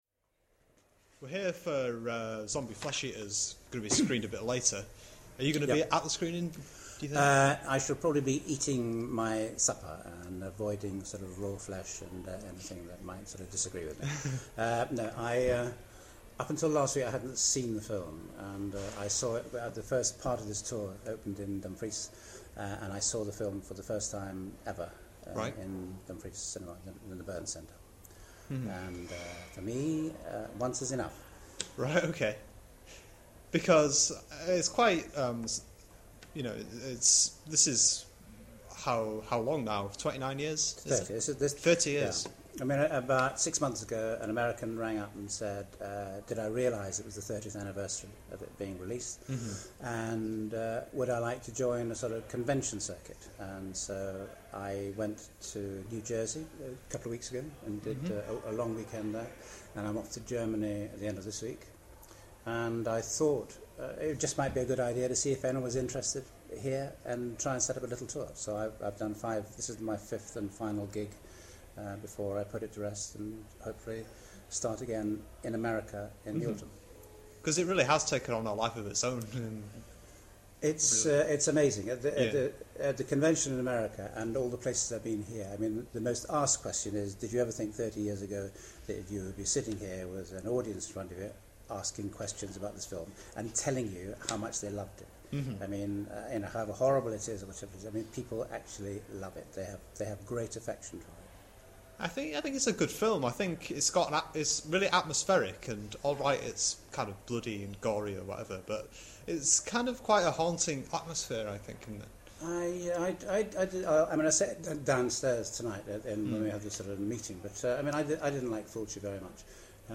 EXCLUSIVE AUDIO INTERVIEW!!!!!!!!!